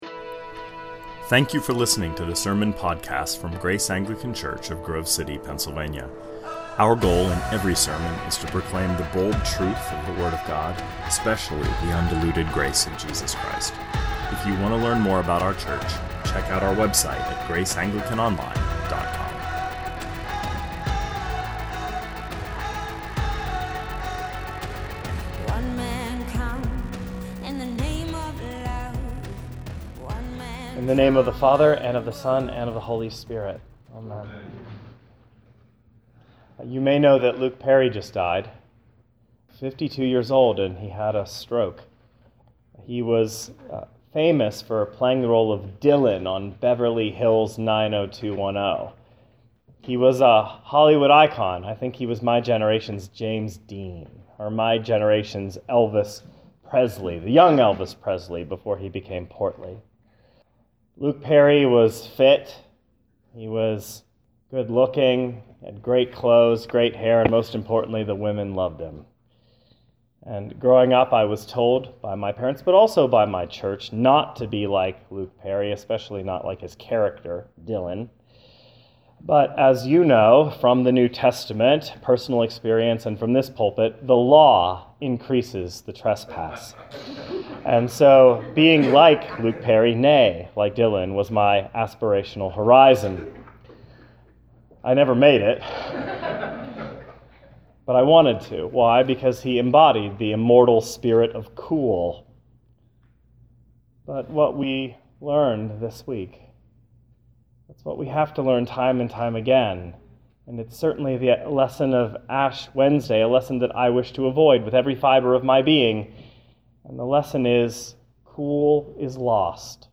An Ash Wednesday meditation